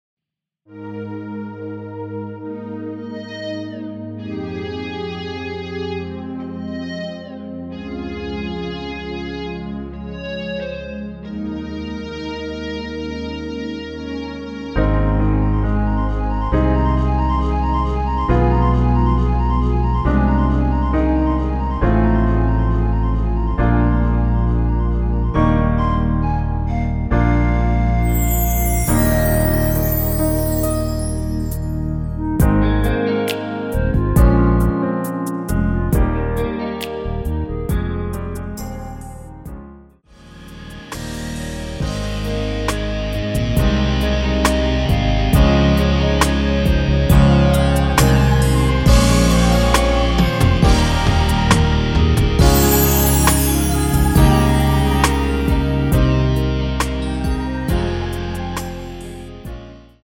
Ab
멜로디 MR이라고 합니다.
앞부분30초, 뒷부분30초씩 편집해서 올려 드리고 있습니다.